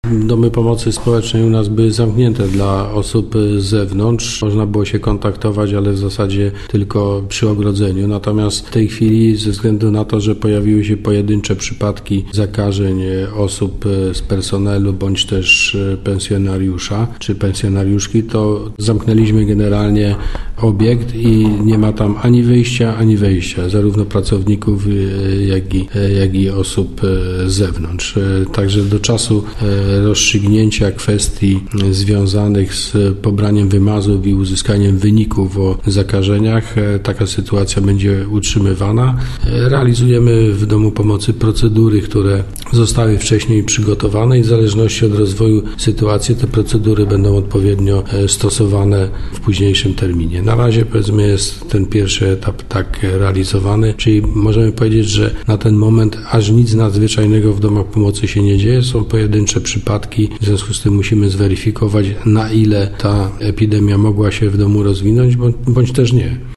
– komentował Andrzej Szymanek, starosta powiatu wieruszowskiego.